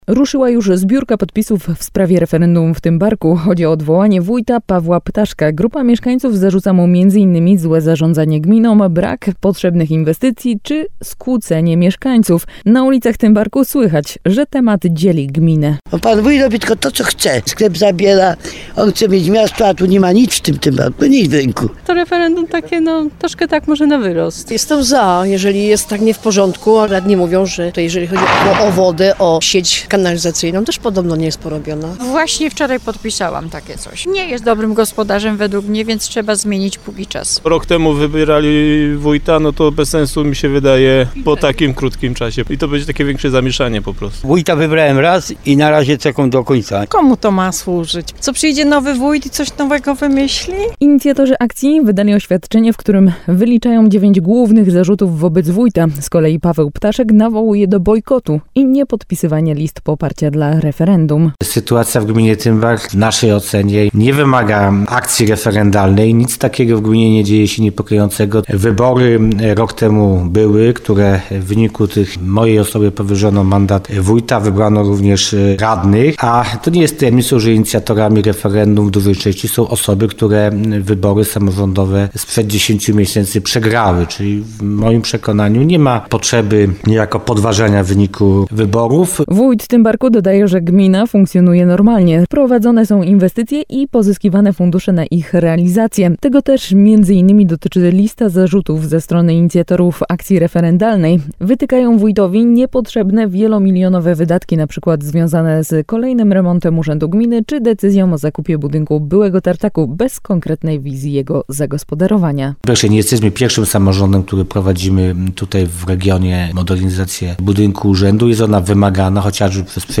Na ulicach Tymbarku słychać, że temat dzieli gminę.